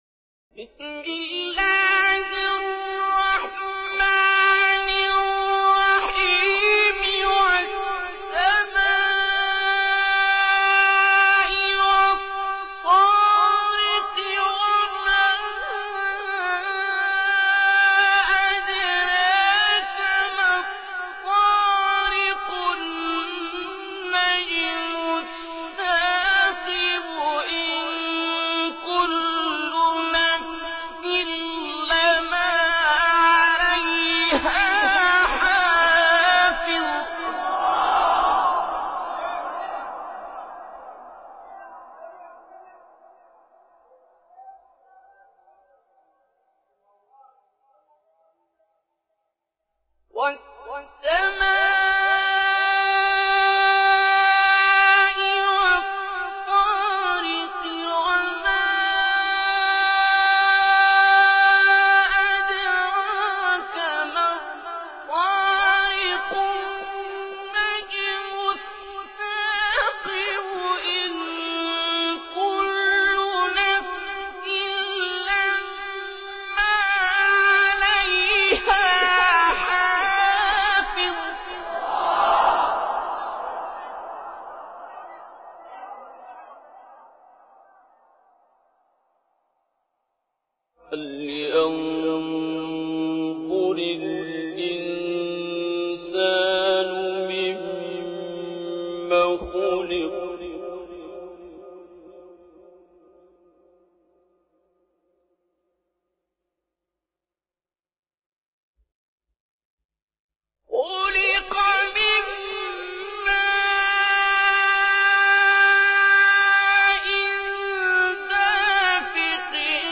sore-taregh-abdol-baset.mp3